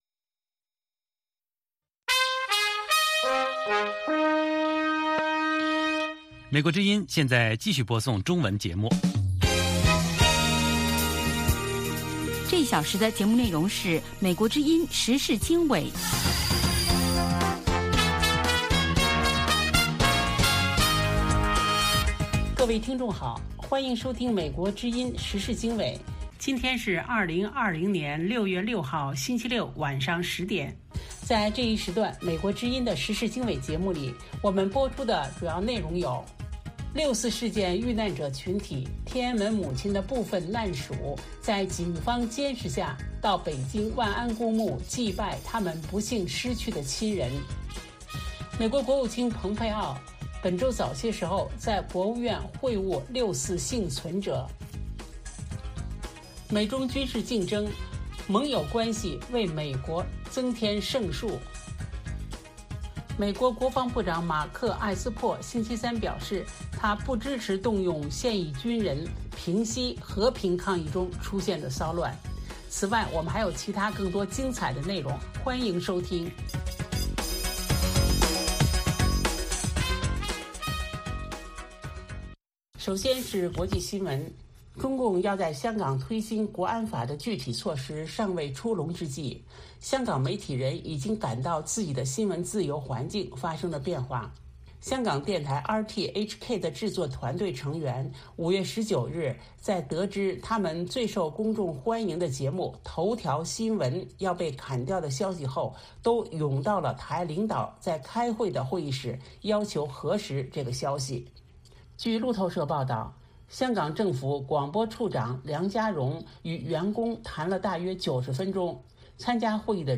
美国之音中文广播于北京时间每天晚上10-11点播出《时事经纬》节目。《时事经纬》重点报道美国、世界和中国、香港、台湾的新闻大事，内容包括美国之音驻世界各地记者的报道，其中有中文部记者和特约记者的采访报道，背景报道、世界报章杂志文章介绍以及新闻评论等等。